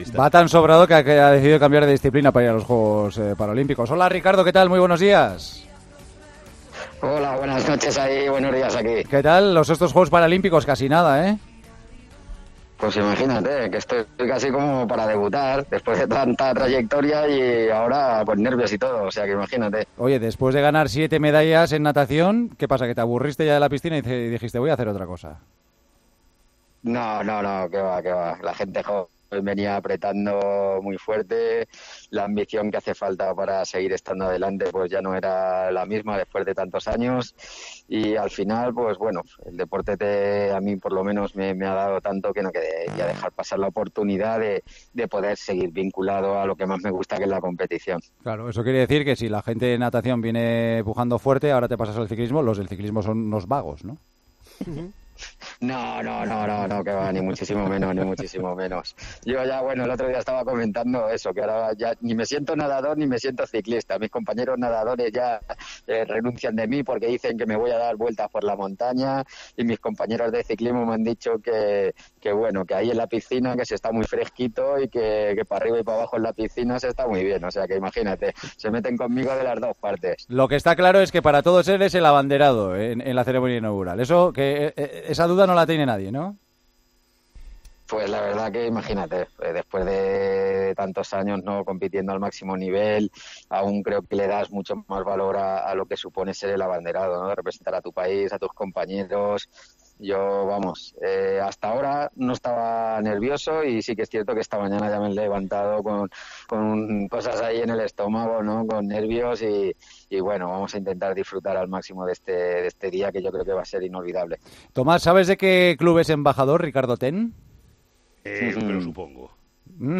AUDIO: Hablamos con el abanderado de la selección española que participará este martes en la inauguración de los Juegos Paralímpicos.